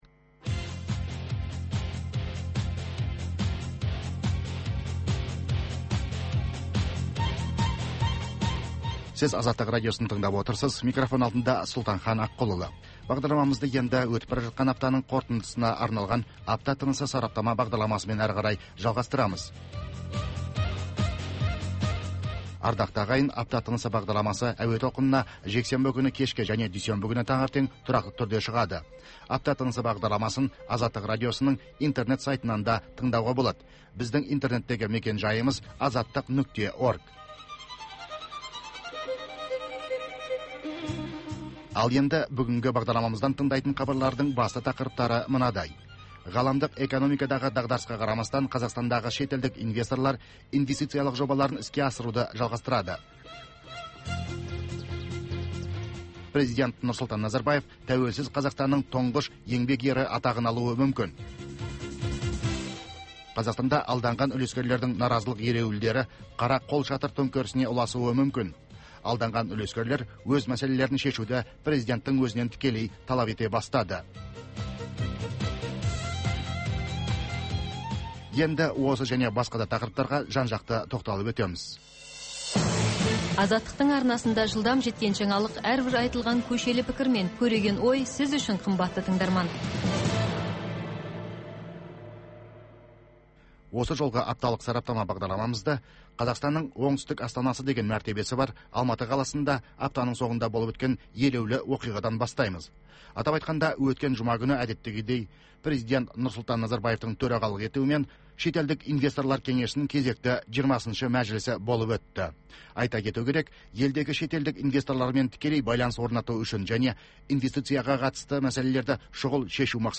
Апта ішінде орын алған елеулі оқиғалар мен өзгеріс, құбылыстар турасында сарапшылар талқылаулары, оқиға ортасынан алынған репортаждардан кейін түйіндеме, пікірталас, қазақстандық және халықаралық талдаушылар пікірі, экономикалық сараптамалар.